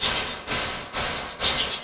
RI_RhythNoise_130-01.wav